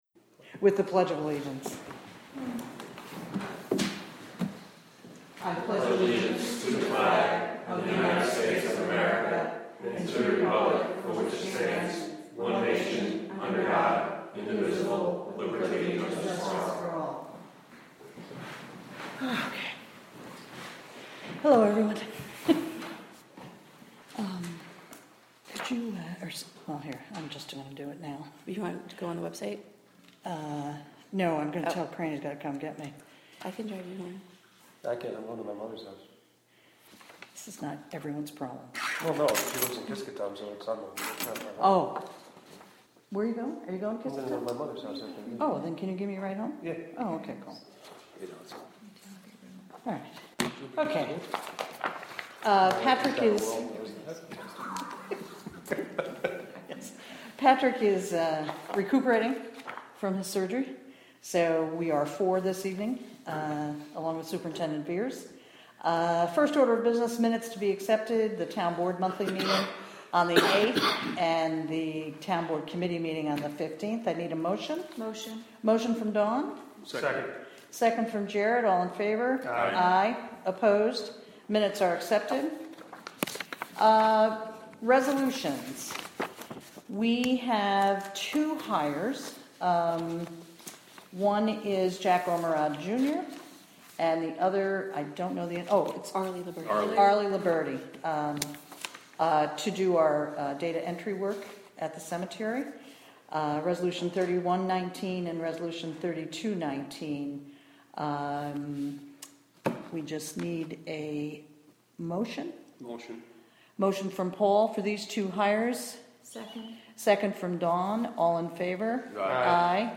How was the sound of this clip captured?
Recorded from a live webstream created by the Town of Catskill through the Wave Farm Radio app. Monthly Town Board meeting.